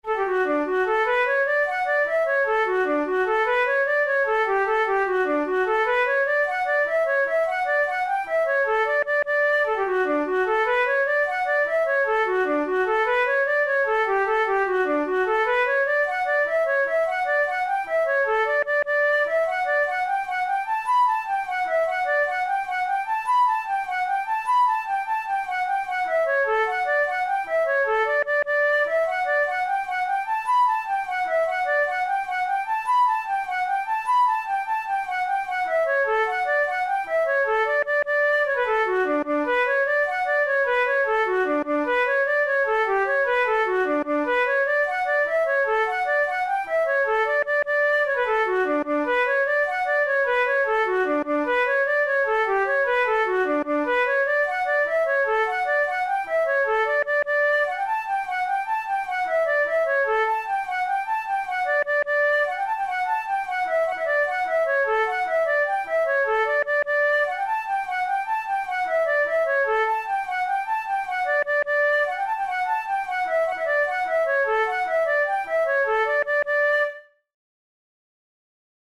KeyD major
Time signature6/8
Tempo100 BPM
Jigs, Traditional/Folk
Traditional Irish jig